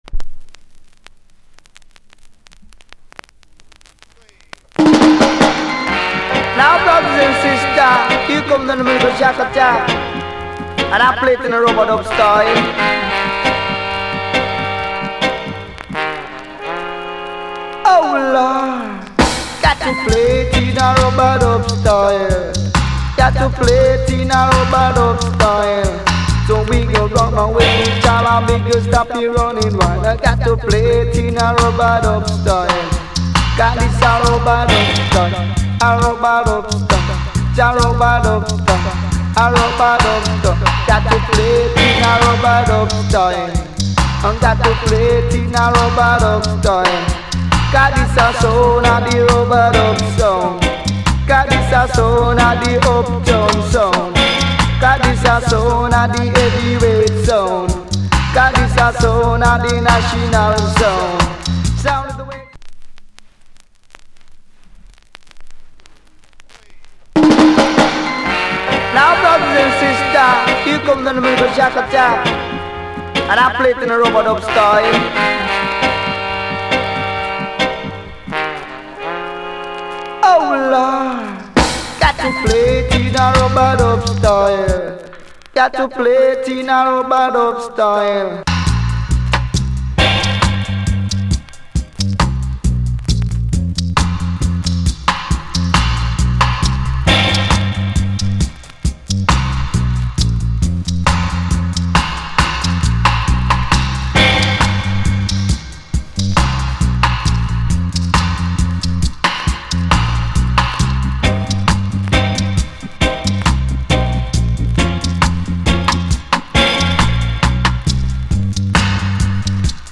Reggae70sLate / Male DJ